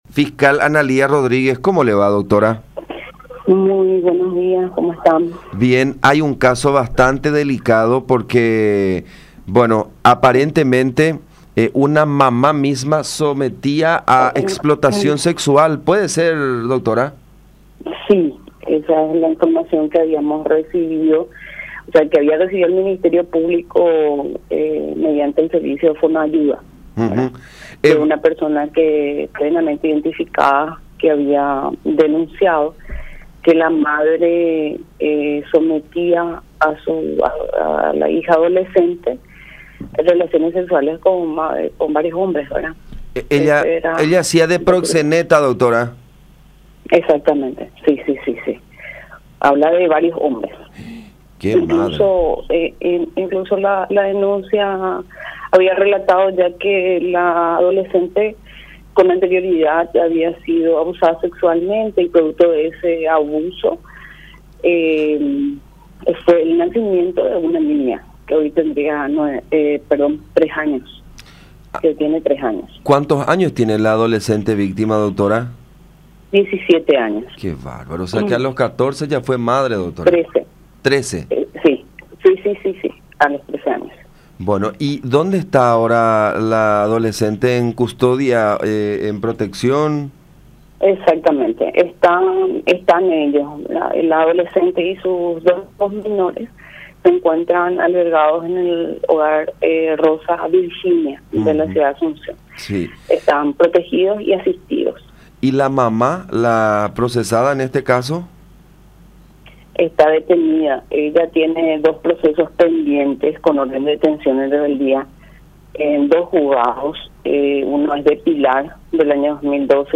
04-FISCAL-ANALÍA-RODRÍGUEZ.mp3